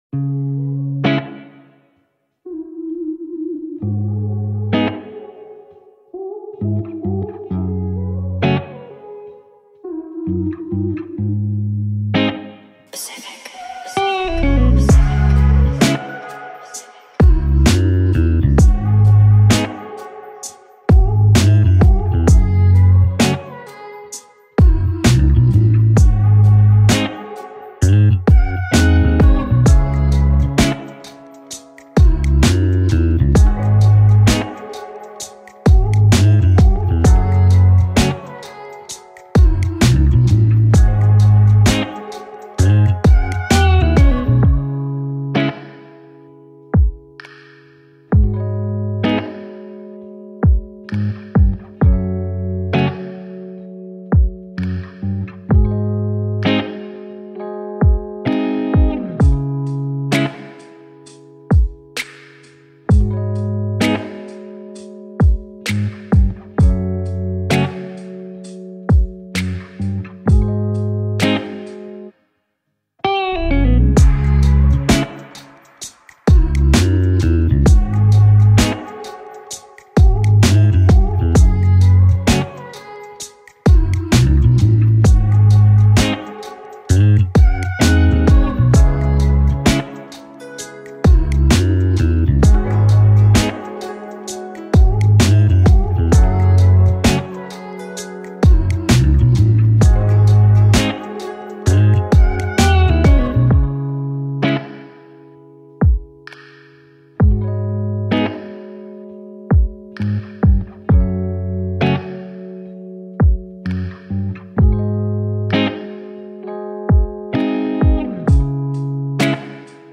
دانلود بیت هیپ هاپ چیل